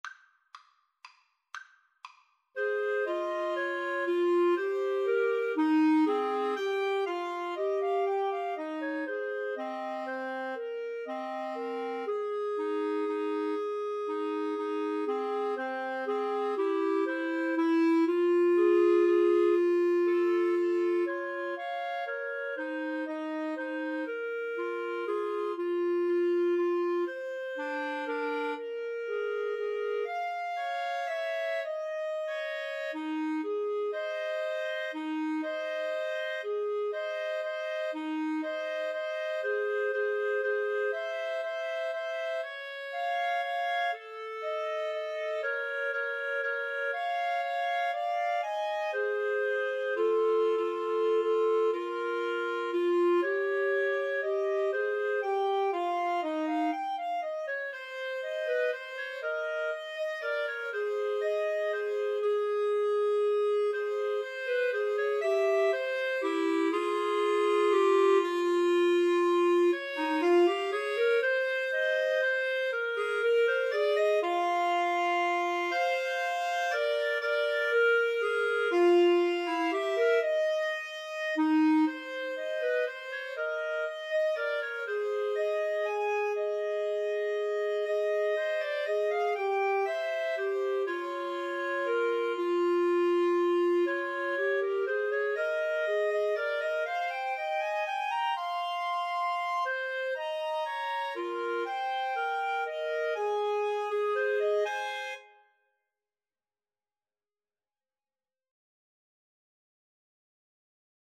3/4 (View more 3/4 Music)
= 120 Tempo di Valse = c. 120
Jazz (View more Jazz Clarinet Trio Music)